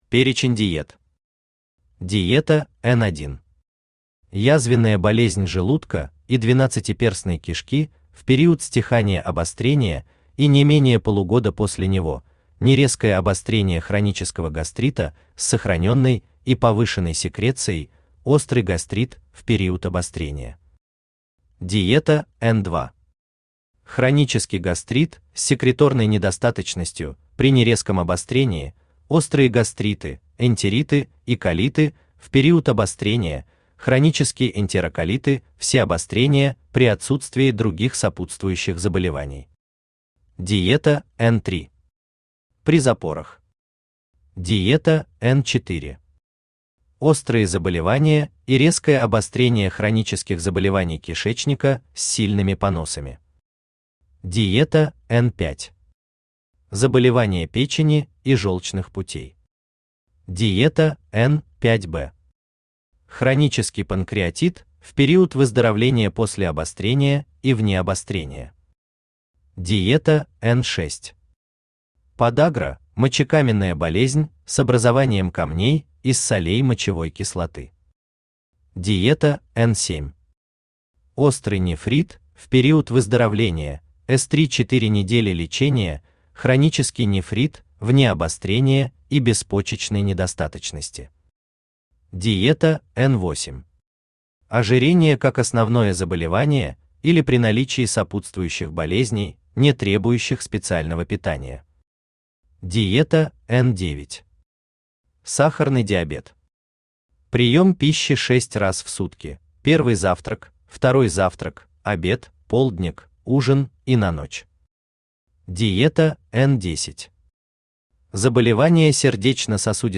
Аудиокнига Приемный покой. Книга 3-1. Диеты, которые вы искали | Библиотека аудиокниг
Диеты, которые вы искали Автор Геннадий Анатольевич Бурлаков Читает аудиокнигу Авточтец ЛитРес.